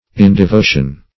Search Result for " indevotion" : The Collaborative International Dictionary of English v.0.48: Indevotion \In`de*vo"tion\, n. [L. indevotio: cf. F. ind['e]votion.] Lack of devotion; impiety; irreligion.